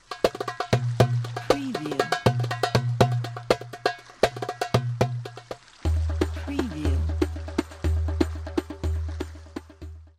مجموعه ریتم عربی
این مجموعه عظیم دارای انواع فیگور های ریتمیک و ادوات مخصوص ریتم های عربی مثل ؛ داربوکا ، دف عربی ، تامبورین ، دُهُل ، تبلا ، بندیر و … می باشید